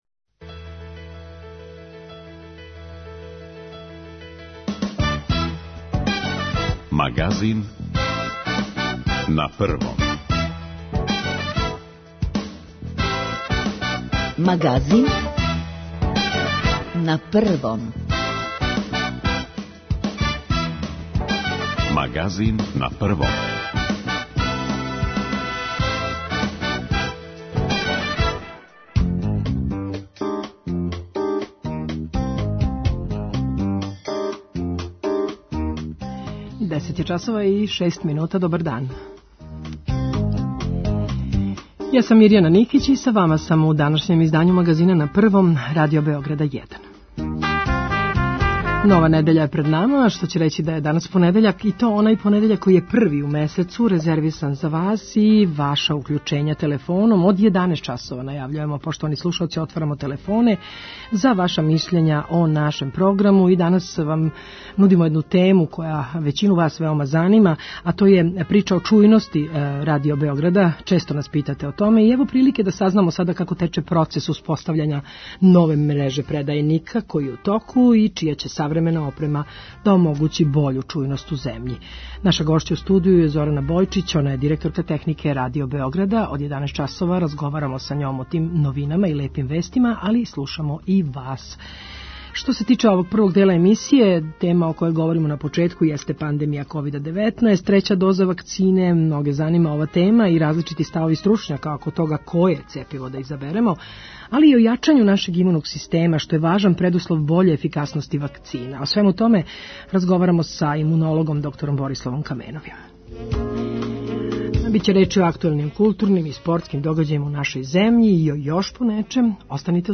И овог пута очекујемо да нам се јавите и кажете своје мишљење о нашем програму. Пошто о проблему чујности Радио Београда често питају управо наши слушаоци, ево прилике да сазнамо како тече процес успостављања нове мреже предајника, чија ће савремена опрема омогућити бољу чујност у земљи и пограничним зонама суседних држава.